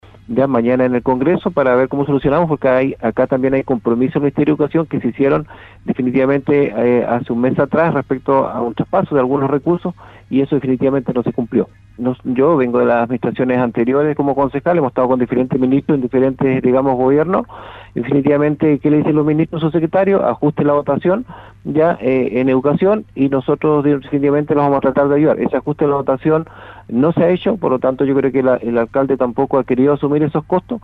Además mañana serán recibidos por la Comisión de Educación de la Cámara Baja en el congreso, en atención a los compromisos de la autoridad de la cartera que no se cumplieron, así lo aseguró el concejal Alex Muñoz.